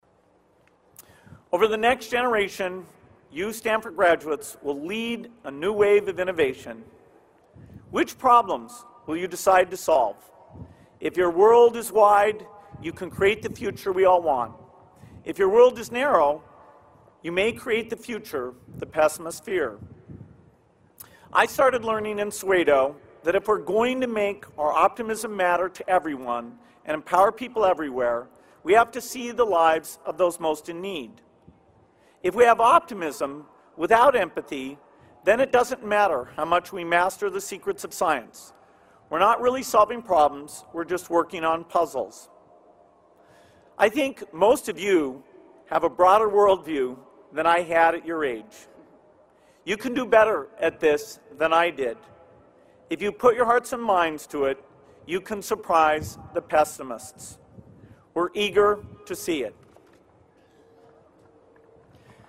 公众人物毕业演讲第37期:比尔盖茨夫妇于斯坦福大学(18) 听力文件下载—在线英语听力室